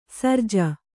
♪ sarja